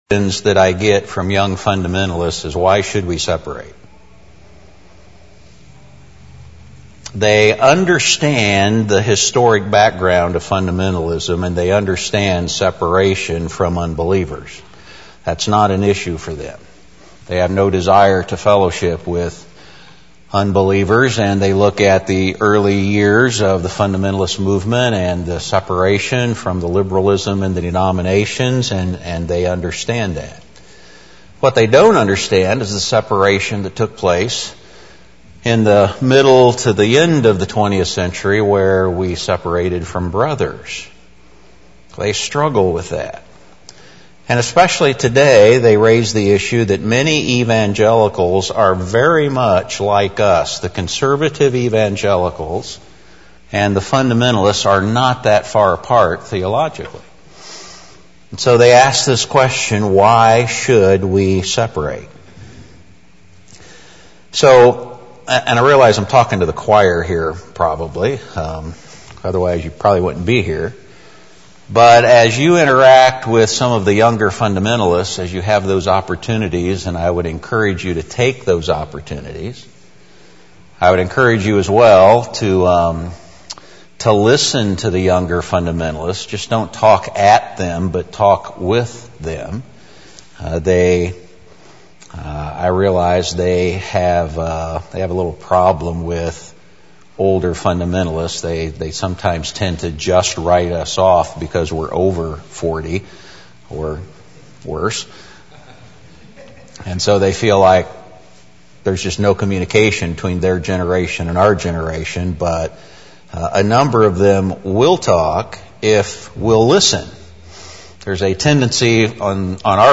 FBFI Northwest Regional Fellowship